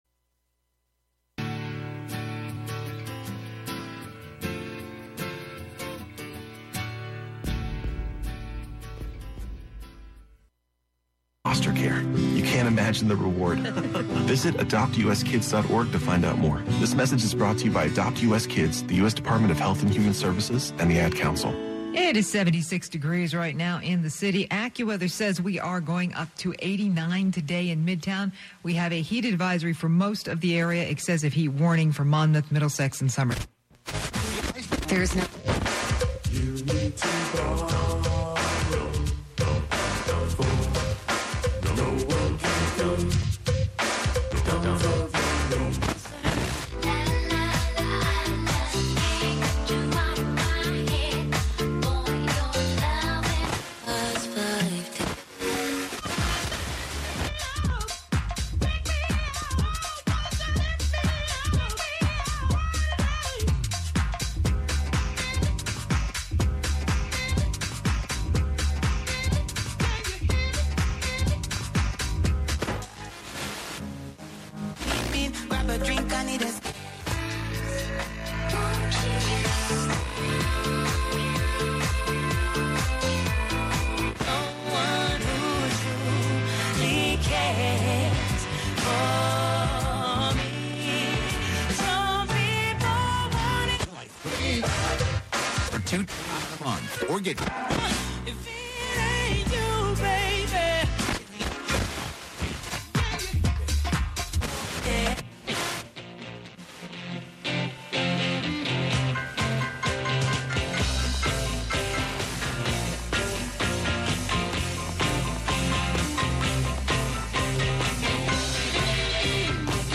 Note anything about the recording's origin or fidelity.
Live from Brooklyn, New York,